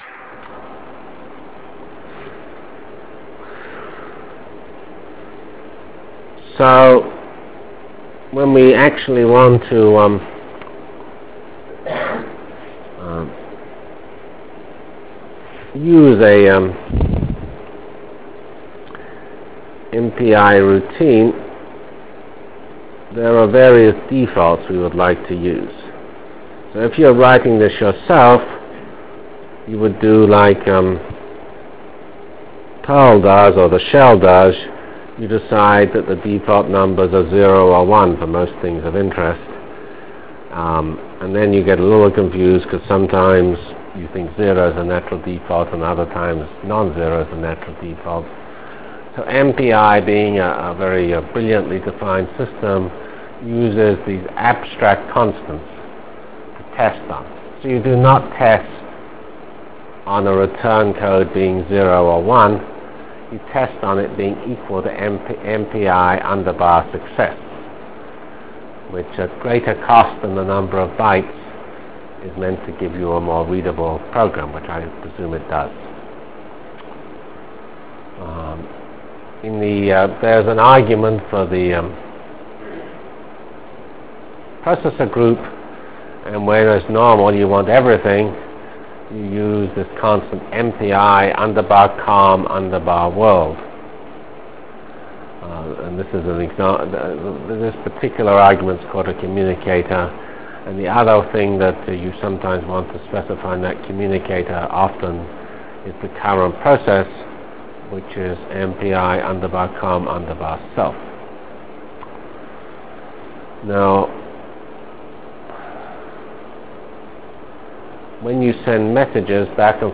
From CPS615-Initial Lecture on MPI ending with discussion of basic MPI_SEND Delivered Lectures of CPS615 Basic Simulation Track for Computational Science -- 31 October 96.